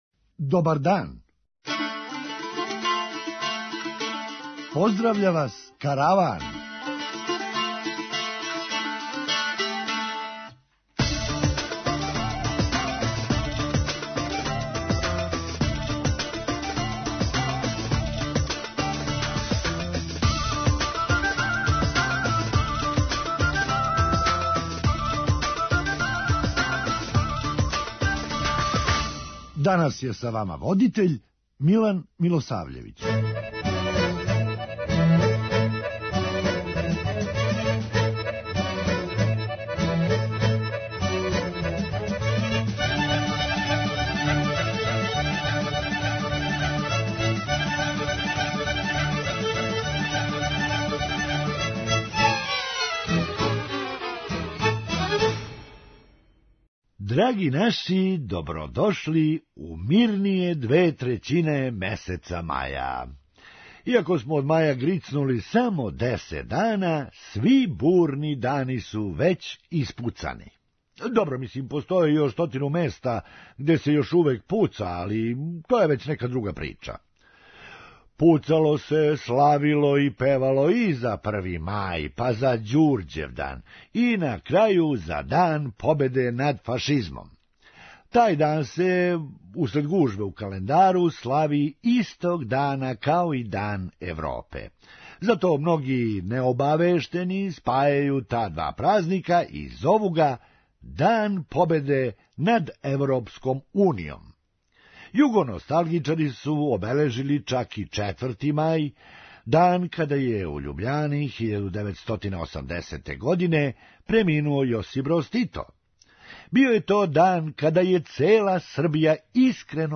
Хумористичка емисија
Познајући Србе на путевима, имали су и друго наоружање. преузми : 8.70 MB Караван Autor: Забавна редакција Радио Бeограда 1 Караван се креће ка својој дестинацији већ више од 50 година, увек добро натоварен актуелним хумором и изворним народним песмама.